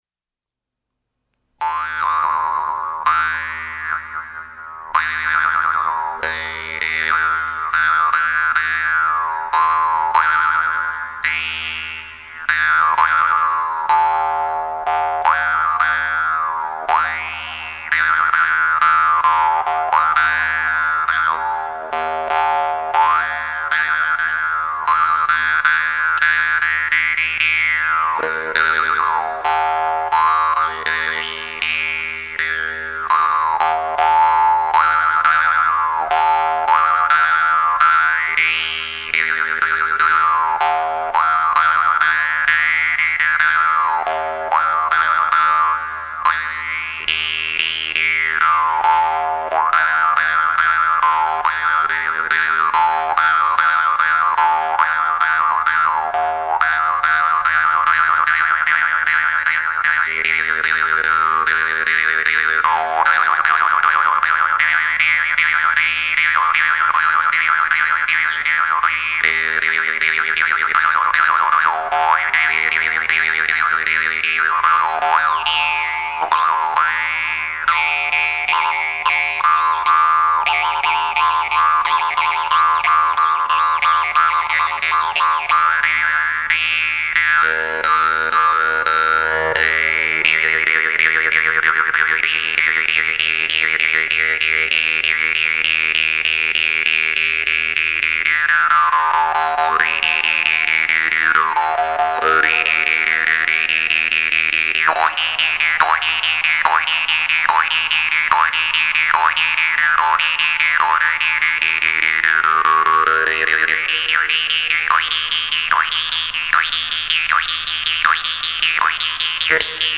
vargan
homus_vargan_-_yakutskij_stil.mp3